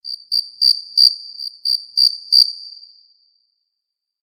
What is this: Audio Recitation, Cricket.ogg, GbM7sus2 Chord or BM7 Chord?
Cricket.ogg